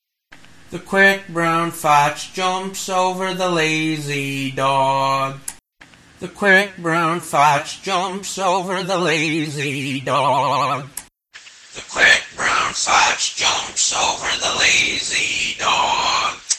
My hoarse effect won’t work with singing, what about vibrato …
Talkbox” (which is like a vocoder) with white noise.